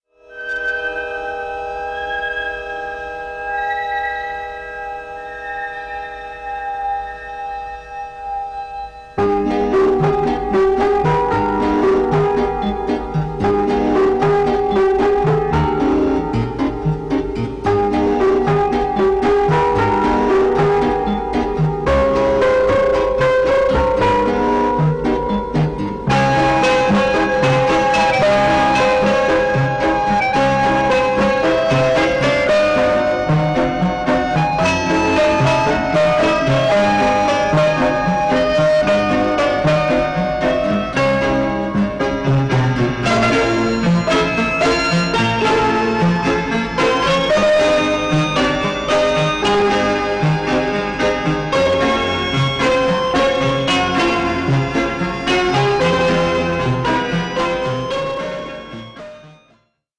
con famoso ritornello.
Track Music